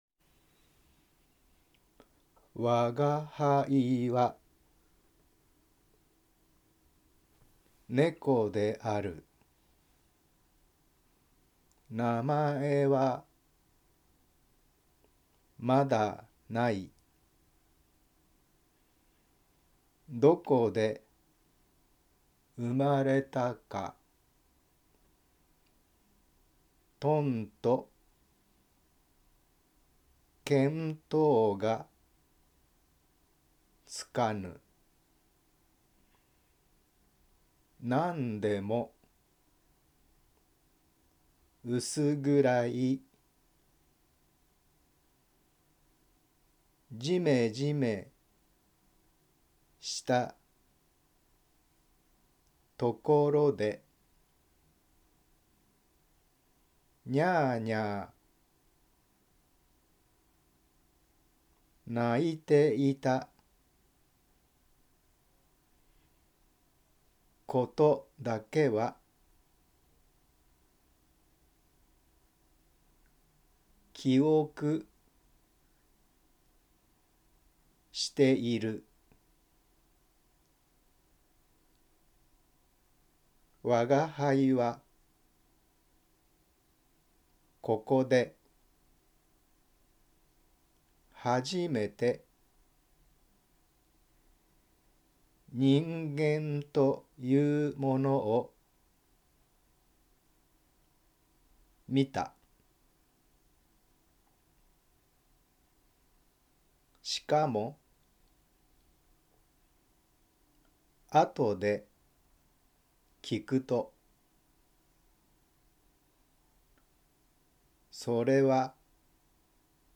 冒頭からゆっくり朗読してみました。
「青空文庫」収載のものを朗読しています。